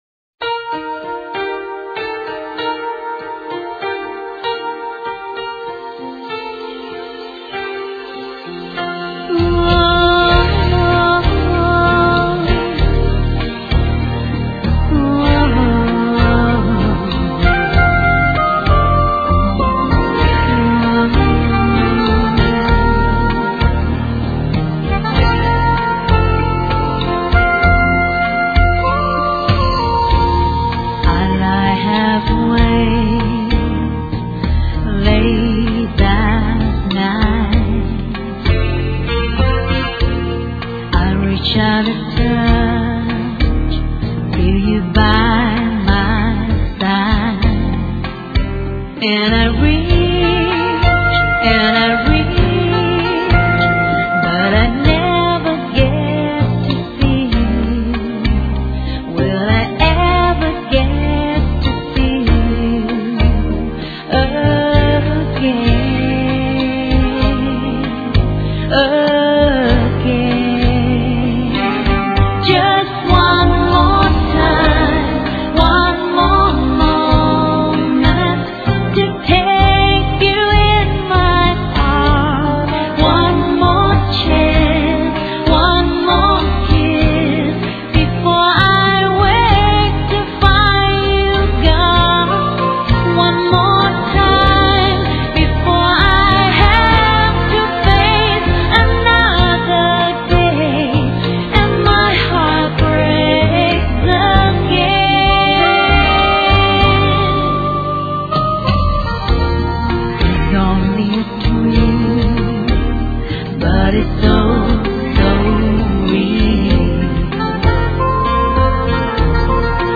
* Thể loại: Nhạc Ngoại Quốc